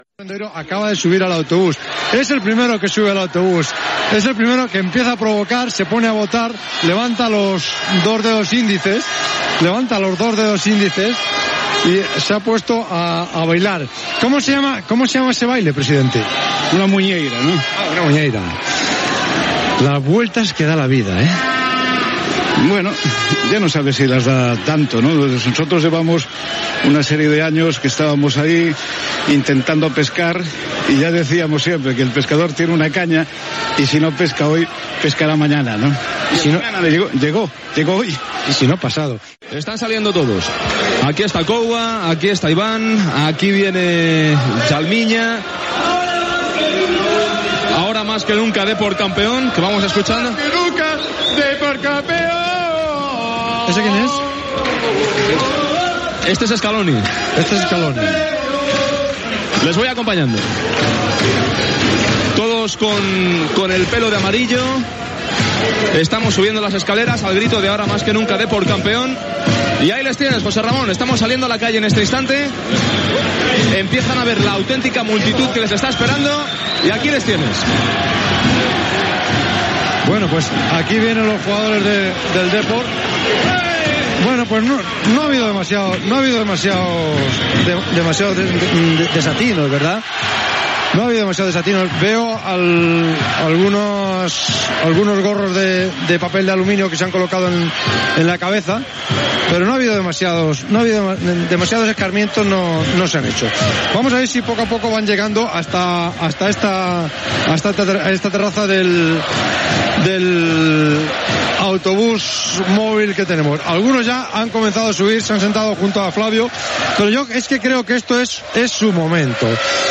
Transmissió de la celebració del títol de lliga de primera divsió de futbol masculí per part del Deportivo de La Coruña, a la temporada 1999-2000.
Esportiu